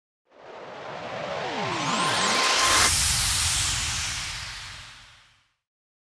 FX-1758-WIPE
FX-1758-WIPE.mp3